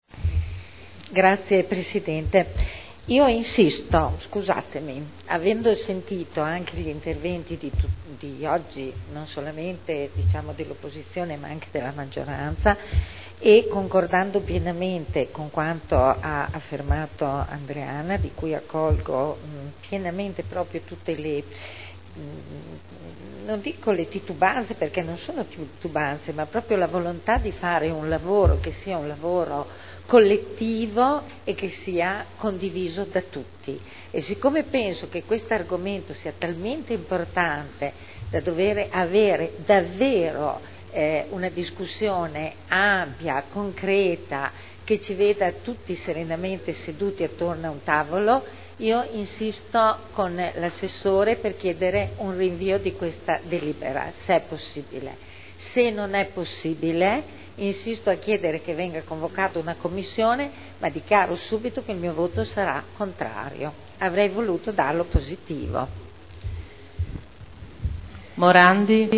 Eugenia Rossi — Sito Audio Consiglio Comunale
Seduta del 13/12/2010 Deliberazione: Approvazione degli indirizzi per la concessione in diritto di superficie di aree comunali Dichiarazioni di voto